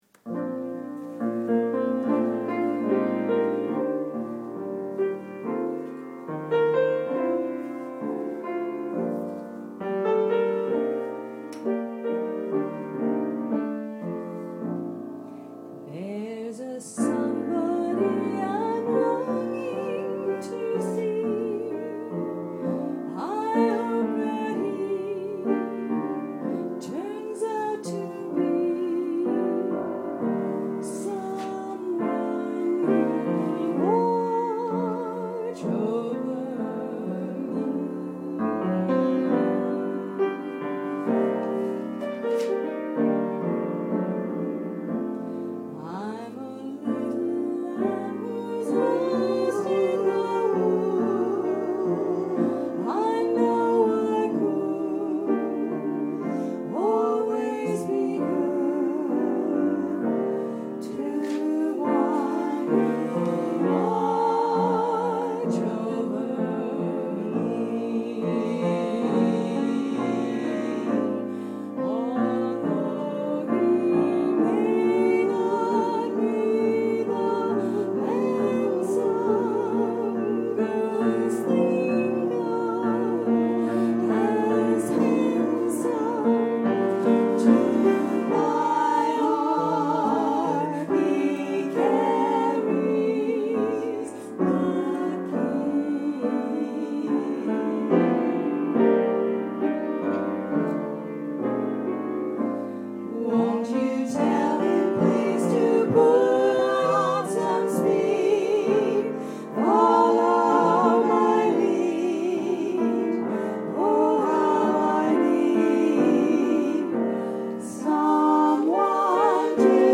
Gershwin for Girls MM + piano AUDIO:
Everyone, all parts